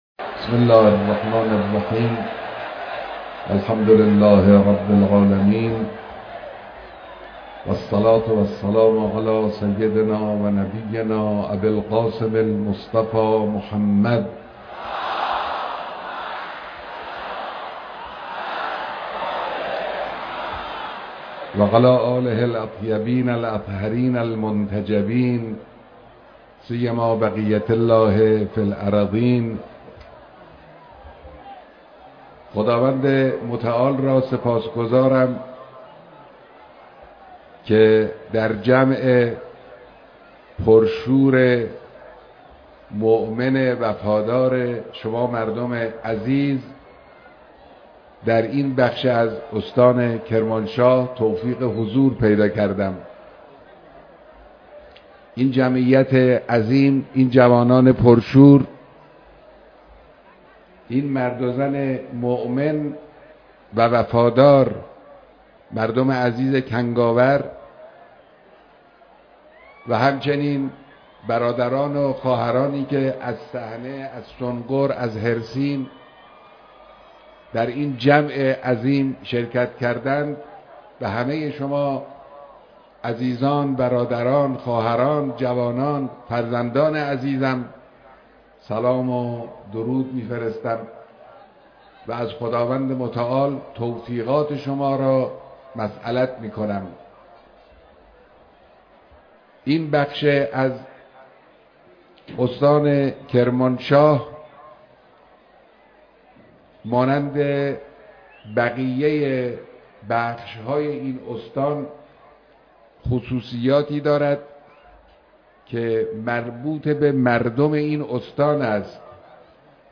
بیانات رهبر معظم انقلاب در دیدار با مردم کنگاور